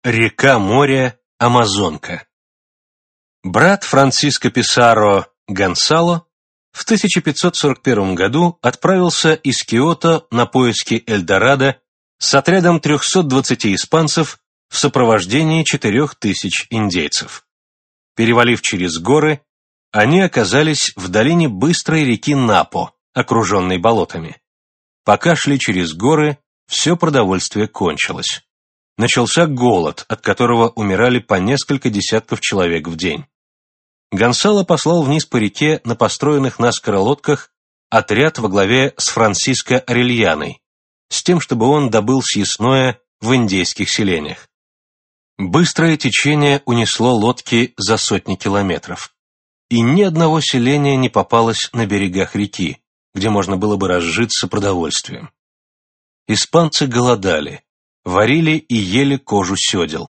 Аудиокнига Постижение планеты | Библиотека аудиокниг